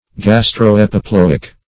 Search Result for " gastroepiploic" : The Collaborative International Dictionary of English v.0.48: Gastroepiploic \Gas`tro*ep`i*plo"ic\, a. [Gastro- + -epiploic.]